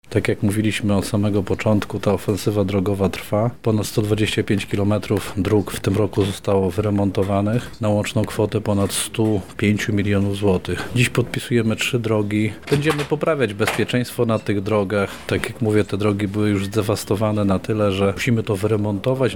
Piotr Breś – o finansach i istotności konserwacji dróg mówił Wicemarszałek Województwa Lubelskiego Piotr Breś.